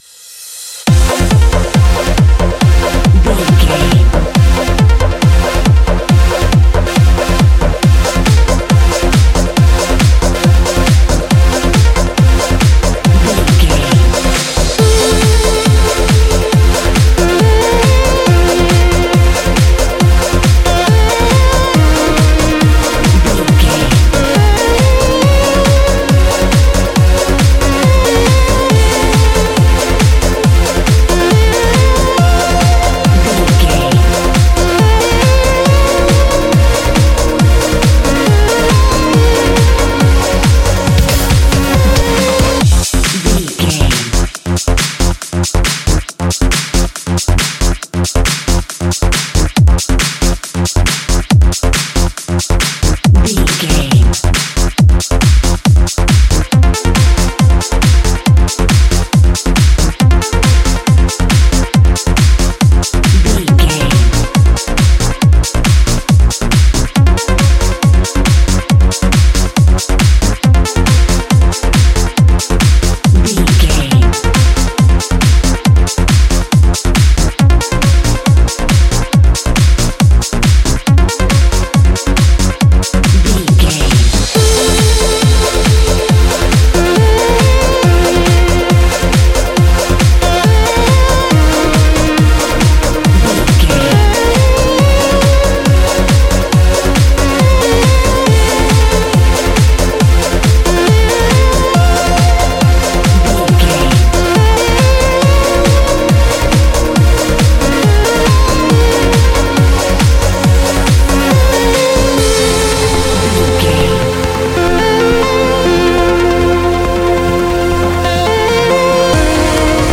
Ethnic Trance Fusion.
Ionian/Major
driving
energetic
hypnotic
uplifting
dreamy
synthesiser
drum machine
synth leads
synth bass